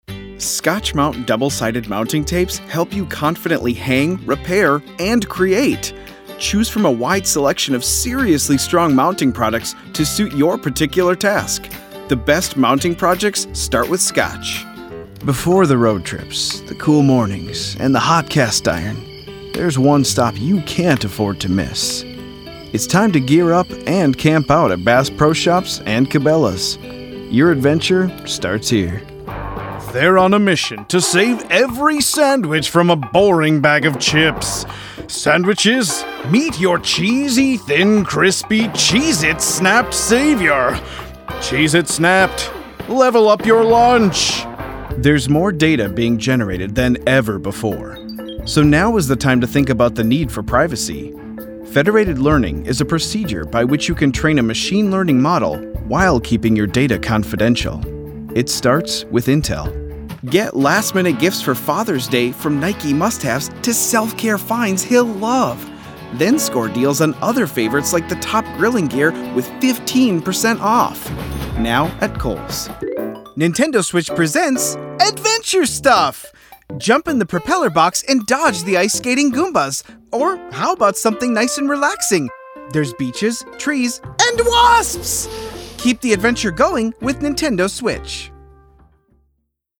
VOICE ACTOR
Commercial Demo
Microphones: RODE NT1, Warm Audio WA-47jr